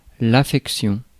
Ääntäminen
IPA: [a.fɛk.sjɔ̃]